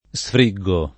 sfriggere [Sfr&JJere] v.; sfriggo [